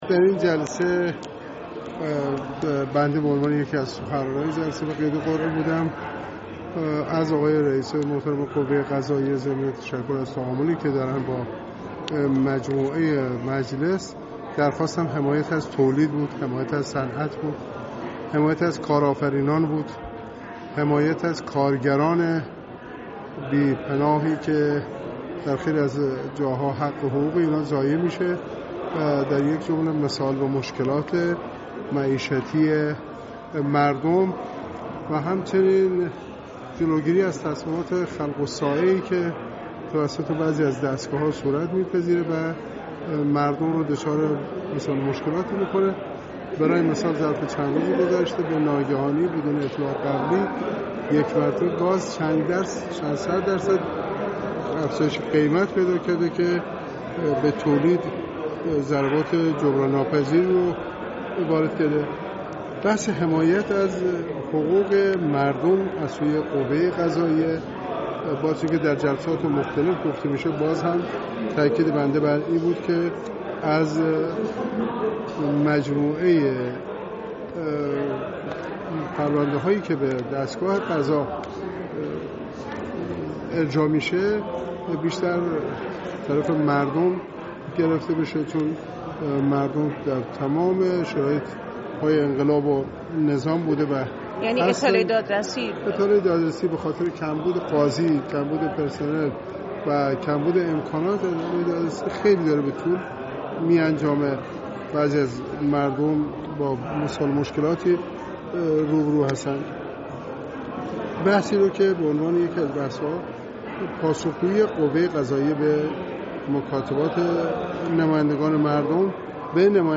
حسن‌پور در گفتگو با فردای‌اقتصاد به این سوالات پاسخ داد: چه سخنانی در جلسه غیرعلنی گفته شد؟